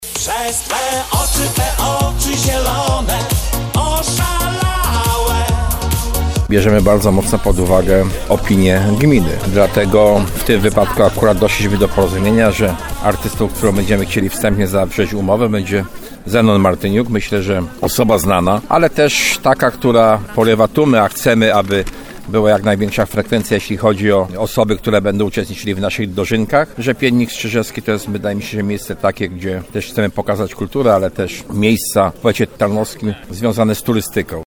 zapowiadał starosta tarnowski Jacek Hudyma.